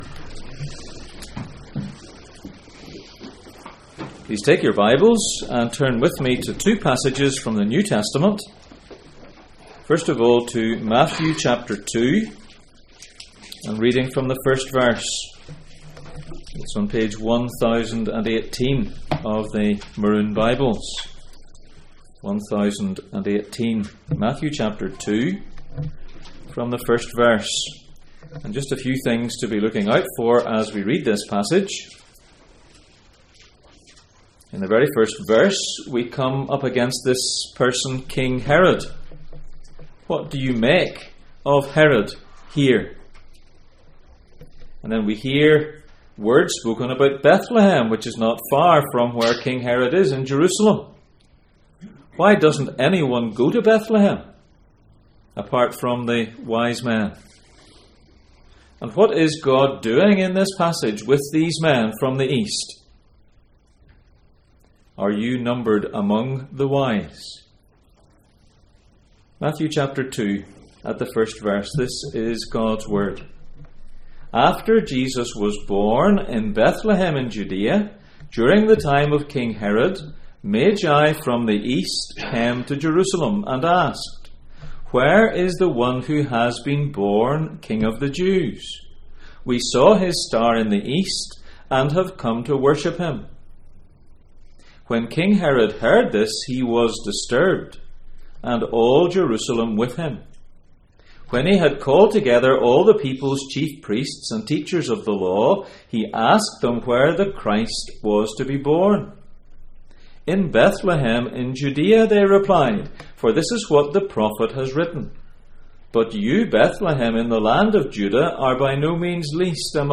Christmas Passage: Matthew 2:1-12, 1 Corinthians 1:18-31 Service Type: Sunday Morning %todo_render% « The Forgotten Christmas Character One message